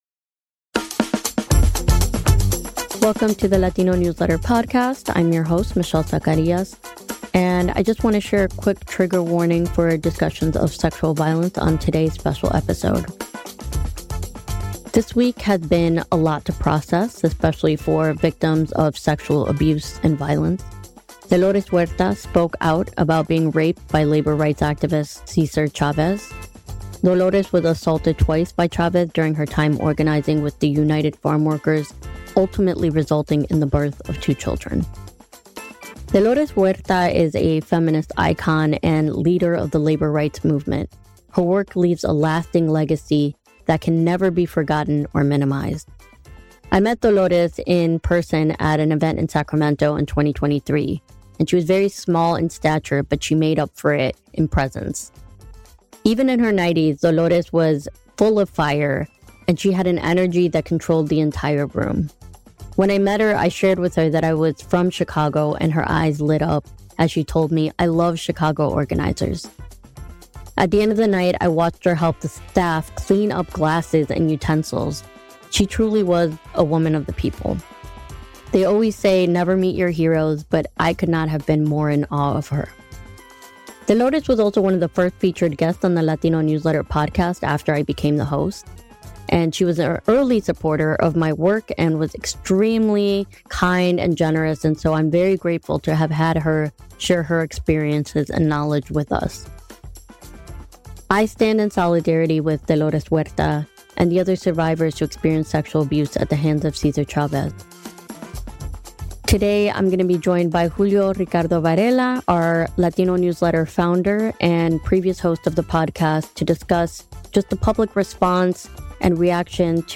The conversation is raw, honest, and deeply felt — a necessary reckoning for the Latino community and beyond.